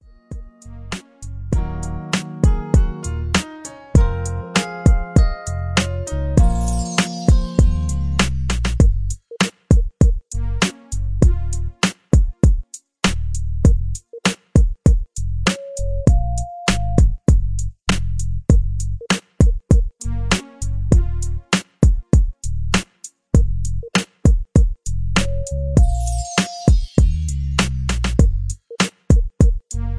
R&B club jam. Something to dance to.